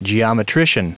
Prononciation du mot geometrician en anglais (fichier audio)
Prononciation du mot : geometrician